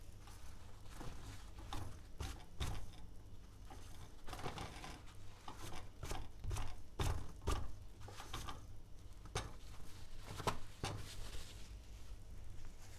Toilet Paper Rolling Sound
household
Toilet Paper Rolling